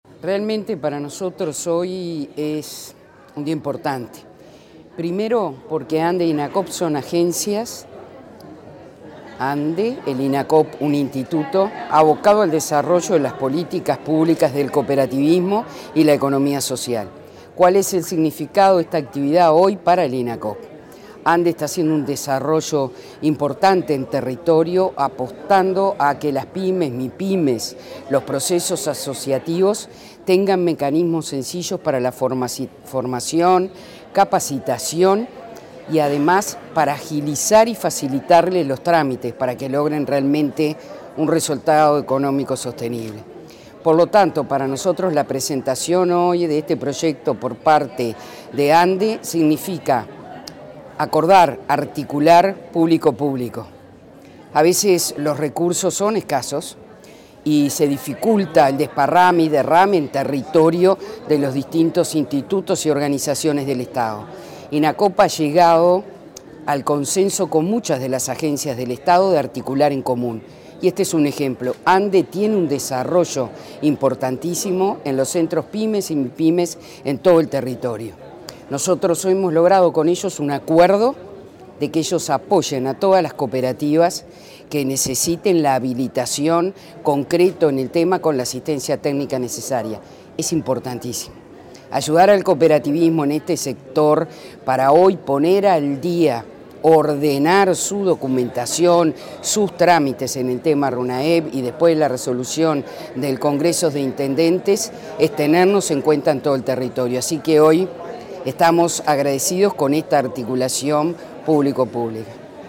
Declaraciones de la presidenta del Instituto Nacional del Cooperativismo, Graciela Fernández